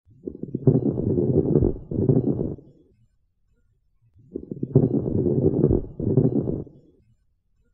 Hemothorax_Audio.mp3